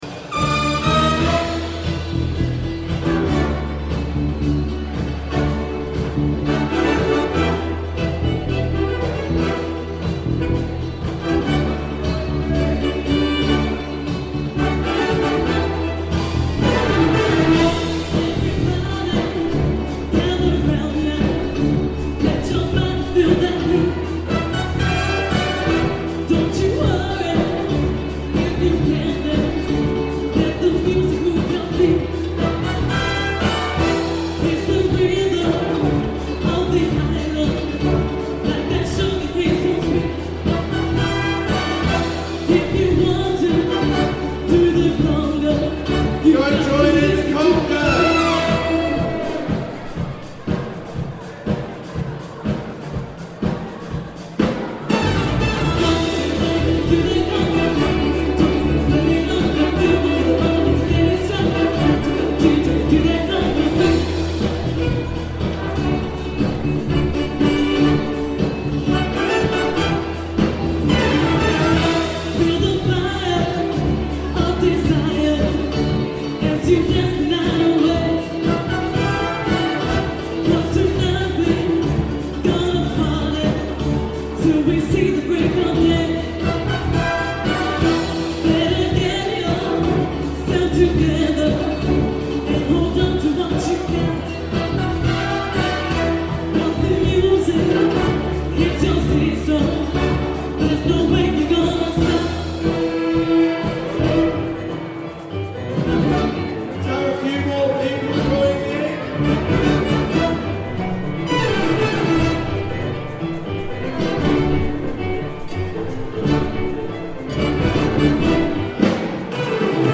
Conga - Big Band in Blackpool 2018